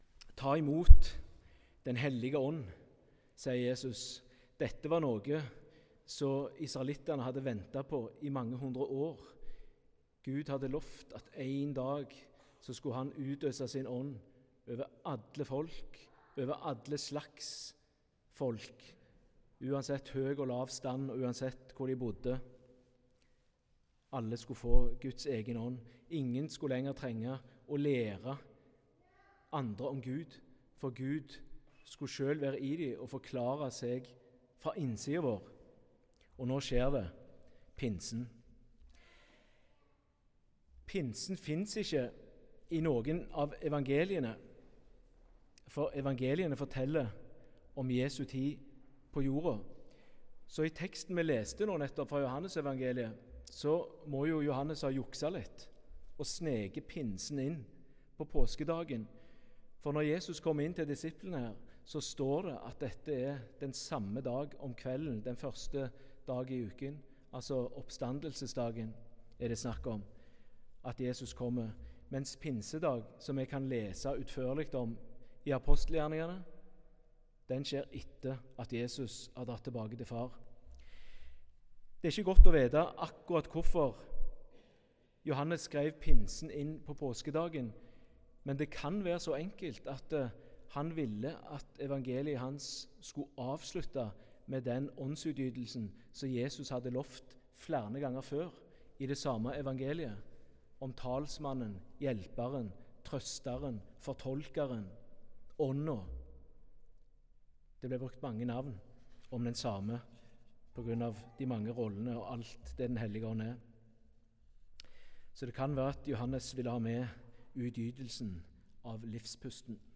Tekstene Evangelietekst: Joh 20,19–23 Lesetekst 1: 1 Mos 2,4b–9 Lesetekst 2: Rom 8,9–11 Utdrag fra talen (Hør hele talen HER ) Påskens punktum Johannes har jukset litt, for han sniker nemlig pinsen inn på påskedagen.